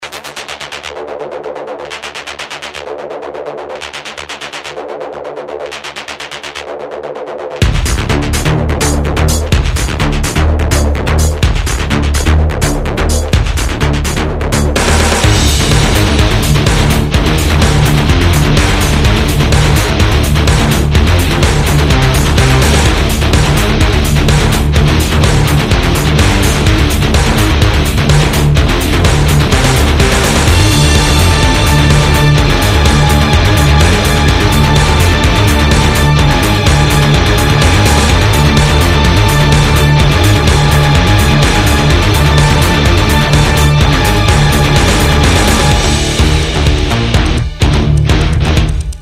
громкие
жесткие
мощные
без слов
электрогитара
heavy Metal